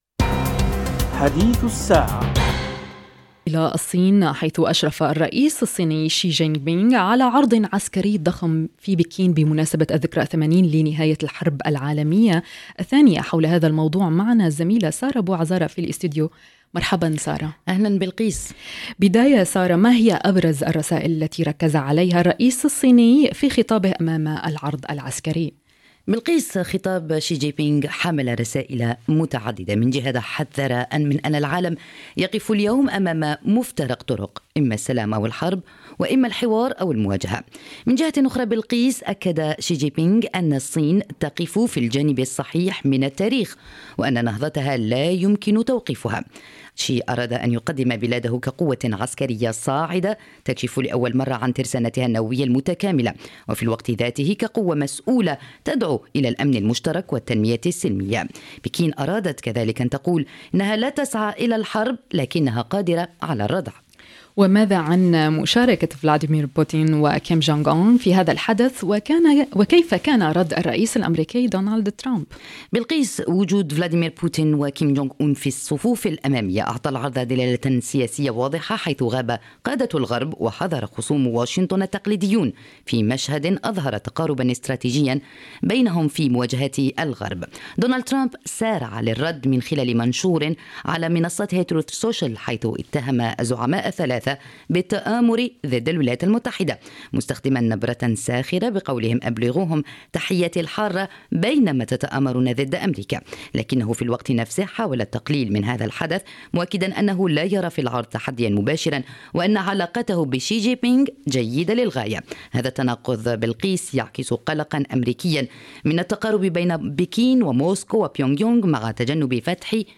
عن هذه المواضيح في حديث الساعة كان نقاش الزميلتين